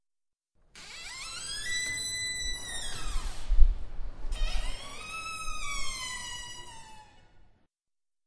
Tànger建筑的声音" 走廊的门
描述：Tanger大楼的地下入口门，UPF，在人通过时打开和关闭。该门位于连接校园和Tanger大楼的走廊中。使用Zoom H4录制声音。